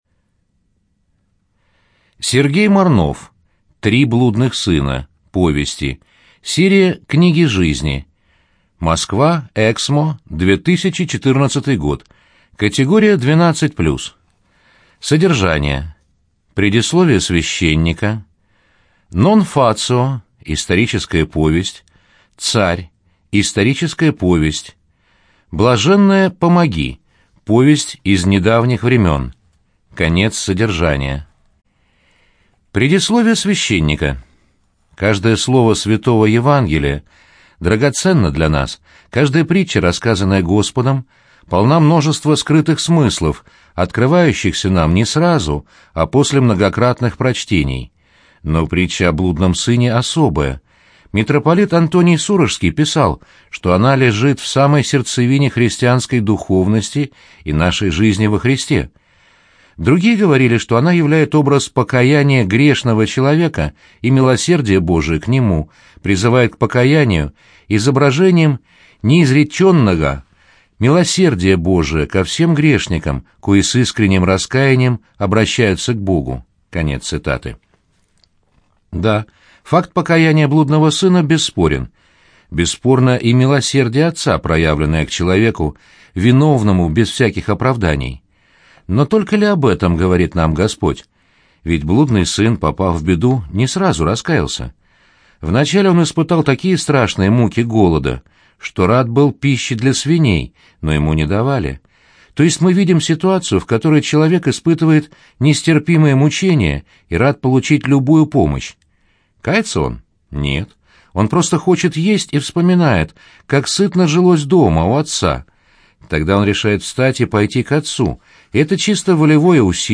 ЖанрИсторическая проза
Студия звукозаписиЛогосвос